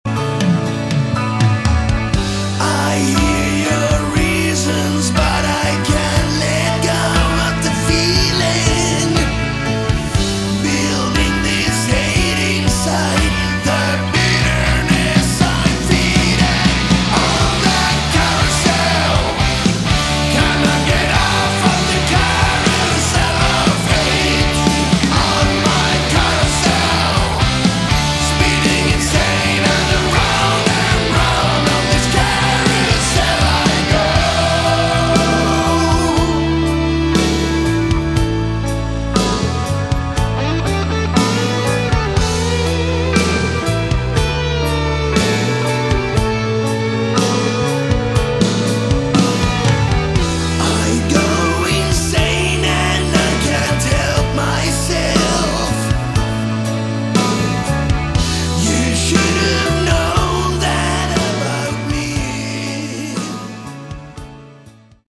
Category: Shock Rock/Horror Rock